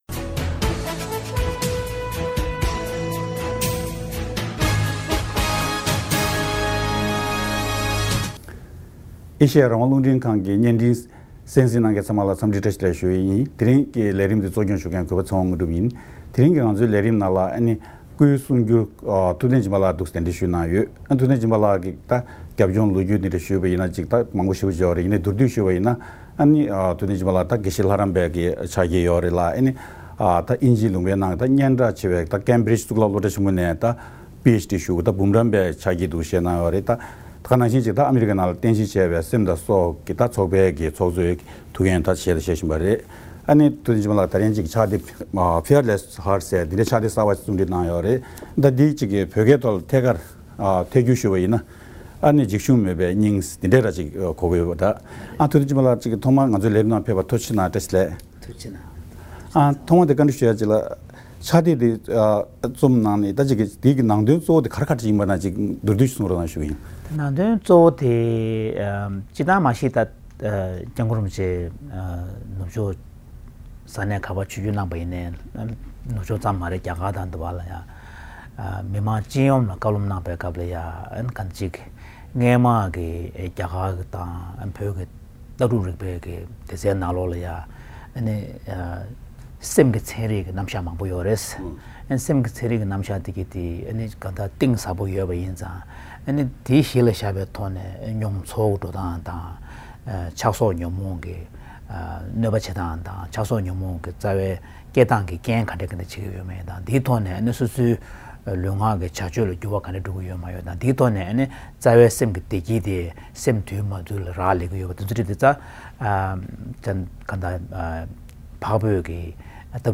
སྐུའི་གསུང་སྒྱུར་བ་ཐུབ་བསྟན་སྦྱིན་པ་ལགས་སུ་གླེང་བ།